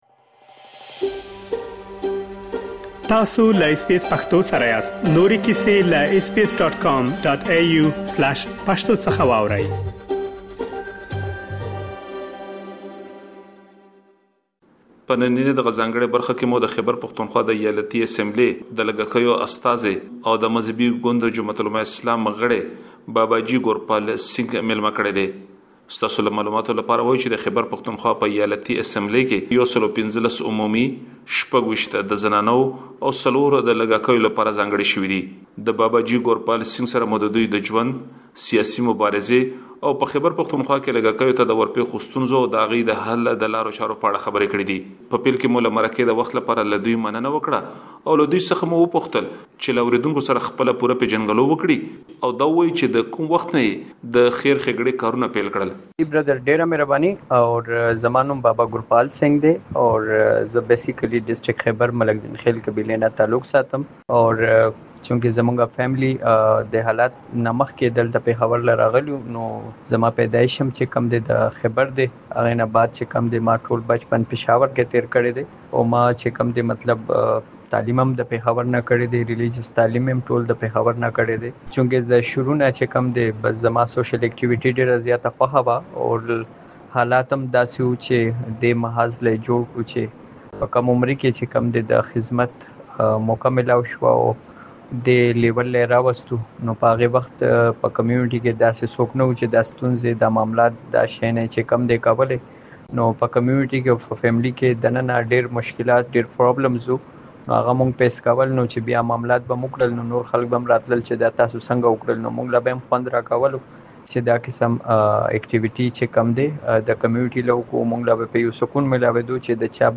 د خیبر پښتونخوا په ولایتي شورا کې د لږکیو غږ ؛ بابا جي ګورپال سینګ سره مرکه